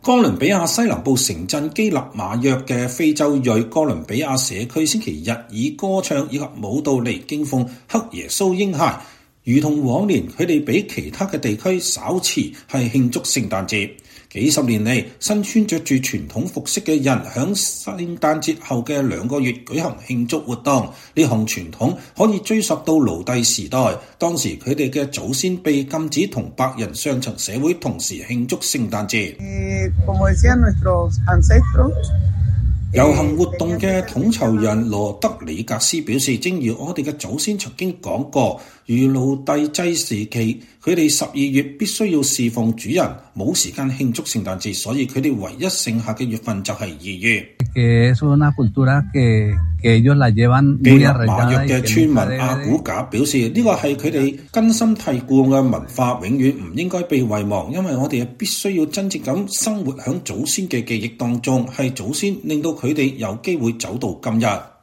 哥倫比亞西南部城鎮基納馬約的非裔哥倫比亞社區週日以歌唱和舞蹈來敬奉黑耶穌嬰孩。如同往年，他們比其他地區稍晚慶祝聖誕節。